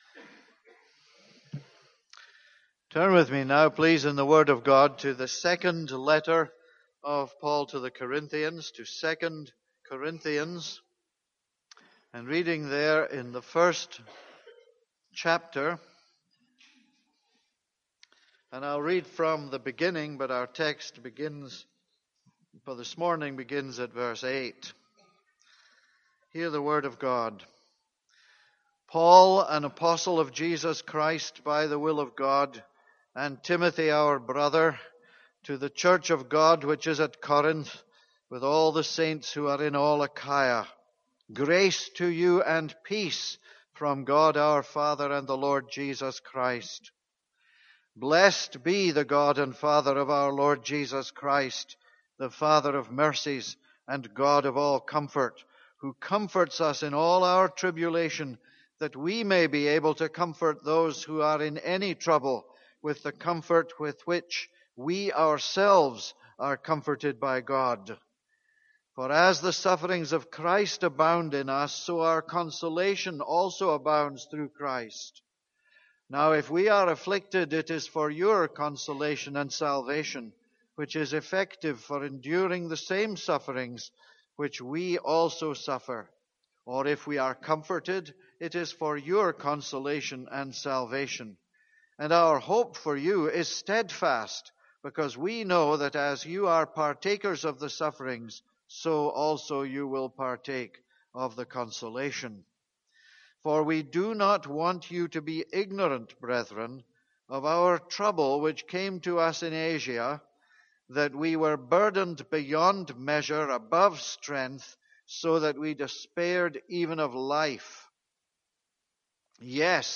This is a sermon on 2 Corinthians 1:8-11.